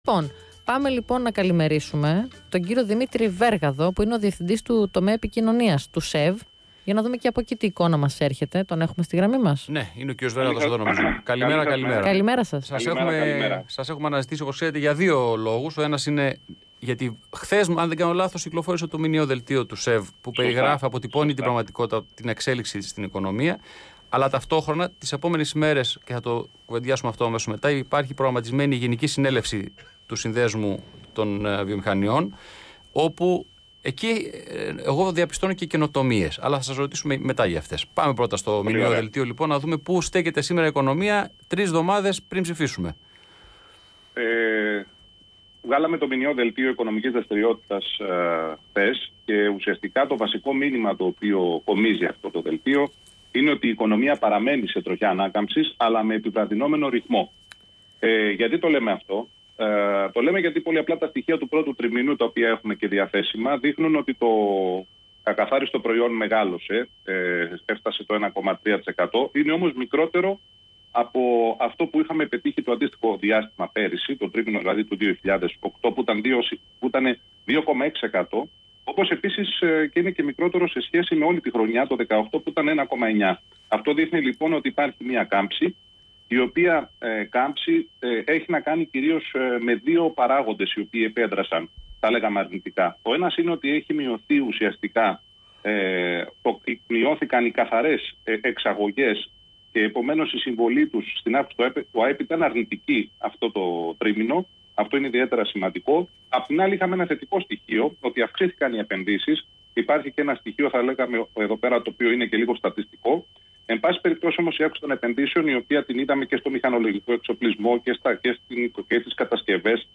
Συνέντευξη
στον Ρ/Σ RADIWFONO247, 88,6